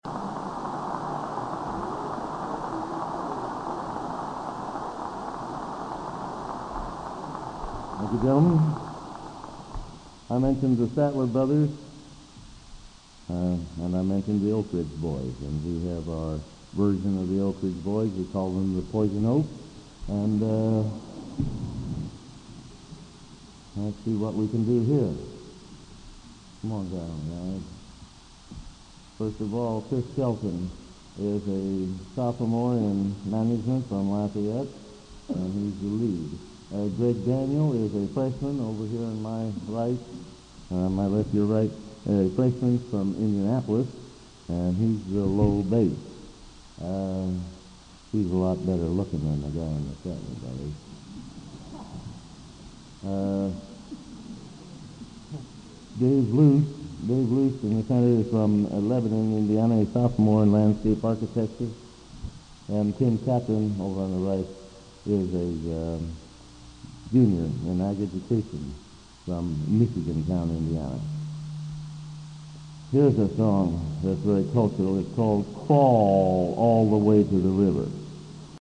Collection: Broadway Methodist, 1982